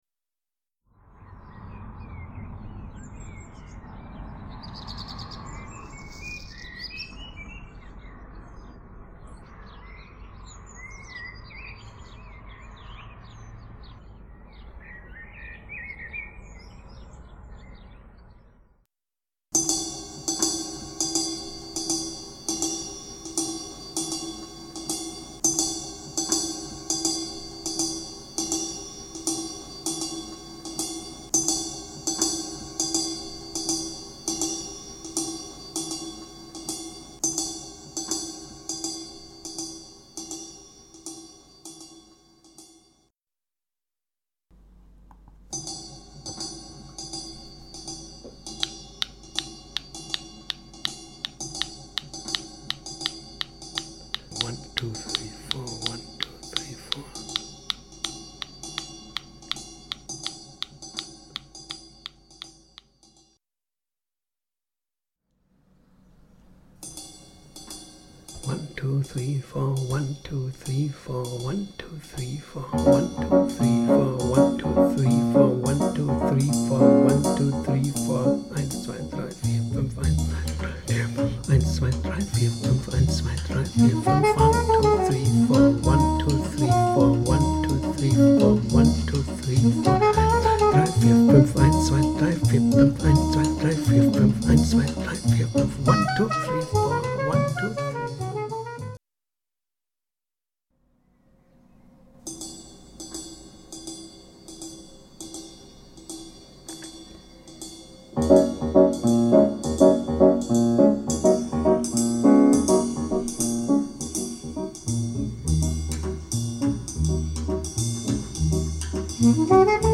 is in 5/4 time.
– singing blackbird in front of my open window
– Beckenrhythmus (mittels copy/paste vervielfältigt)
(mit unterlegtem Zählen, leider teilweise unpräzise)